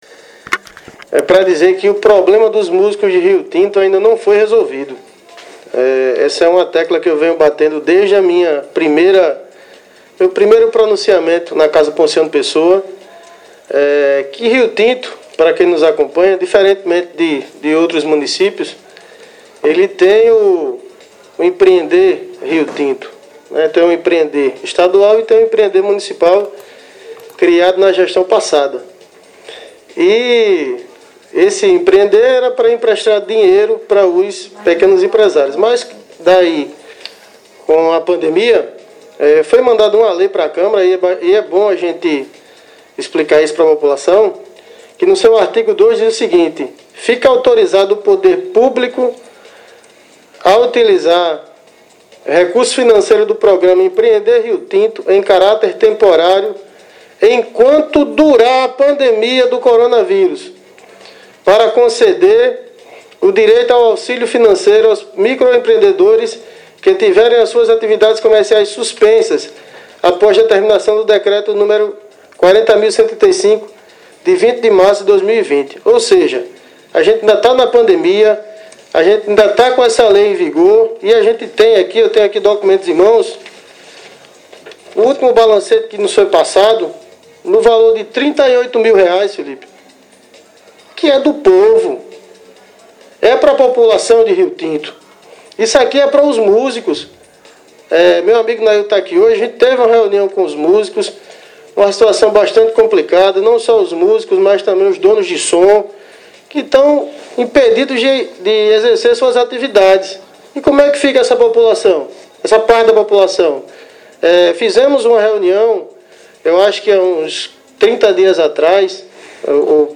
Em entrevista ao Programa Panorama 104 da rádio Litoral Norte Fm, Luan informou que apesar do poder público já está ciente da situação financeira crítica por qual passa os músicos, o problema ainda não foi resolvido.